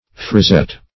Frizette \Fri*zette"\